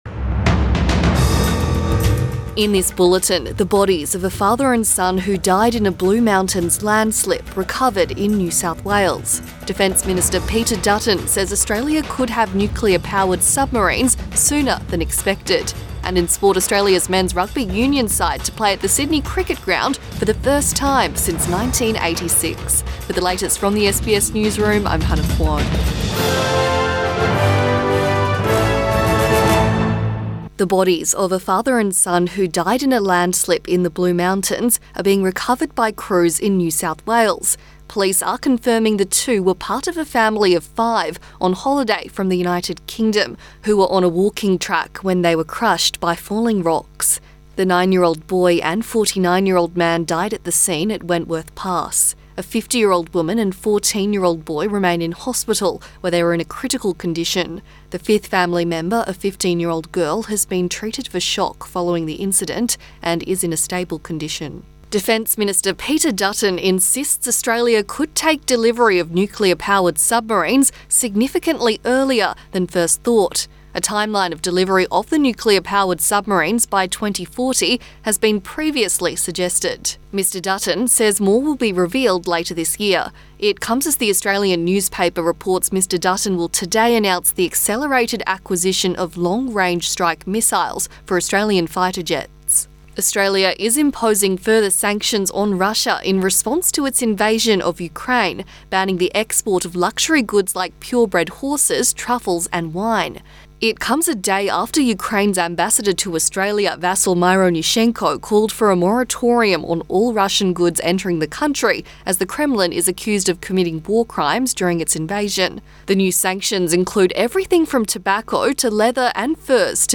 Midday bulletin 5 April 2022